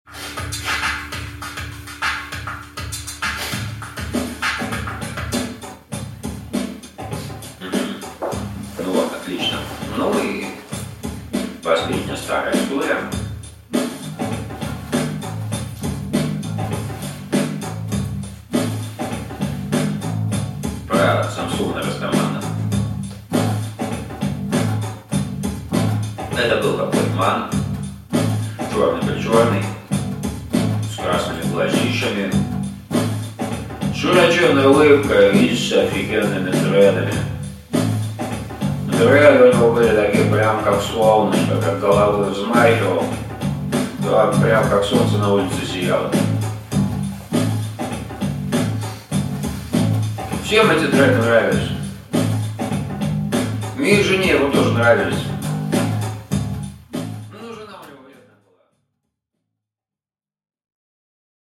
Аудиокнига Про Самсона | Библиотека аудиокниг
Aудиокнига Про Самсона Автор Дмитрий Гайдук.